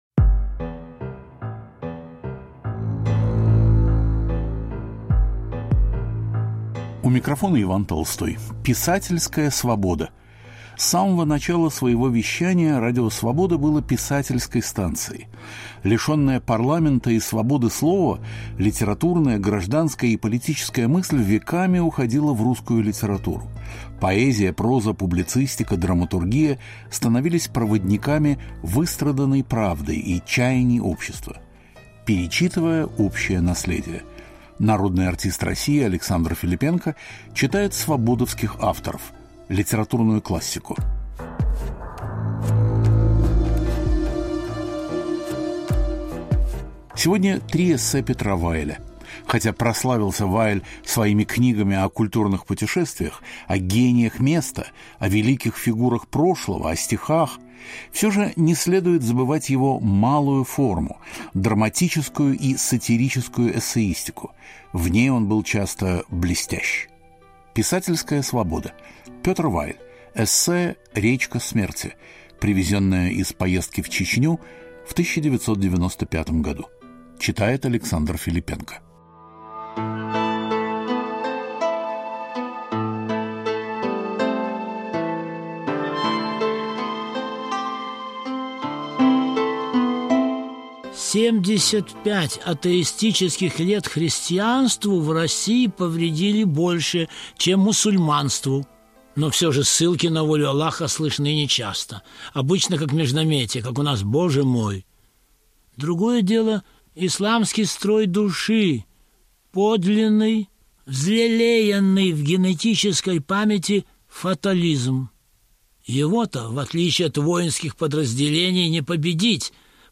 Александр Филиппенко читает эссе Петра Вайля